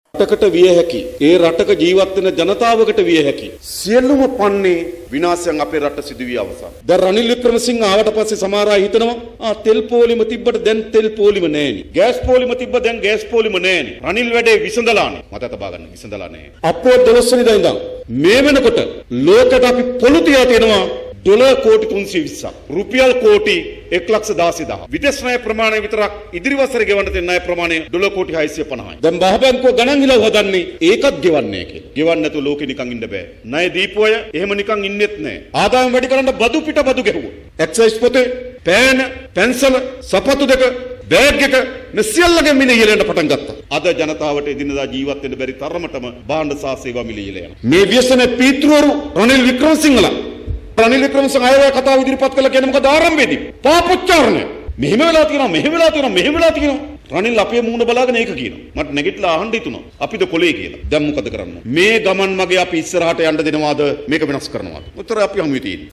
එහි නායක අනුර කුමාර දිසානායක මහතා මේ බව සදහන් කලේ ගාල්ල ආසන සමුළුවට එක්වෙමින් .